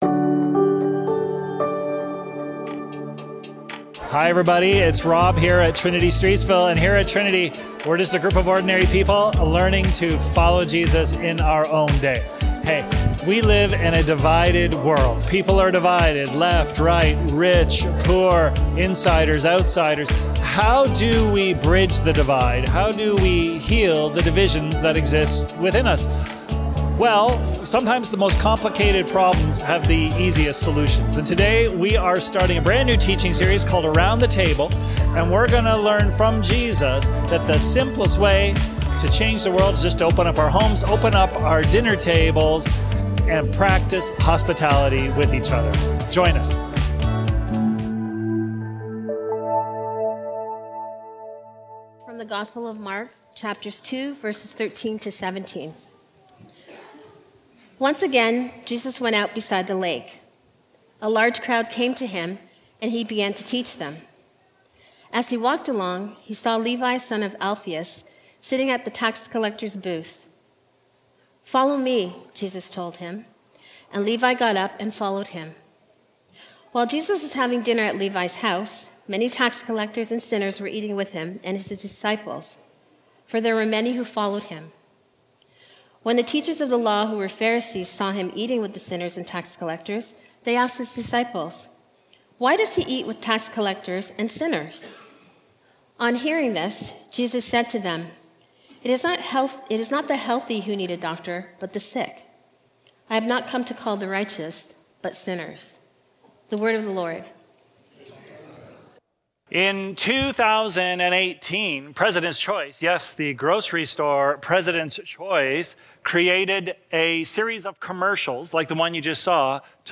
Trinity Streetsville - Radical Ordinary Hospitality | Around The Table | Trinity Sermons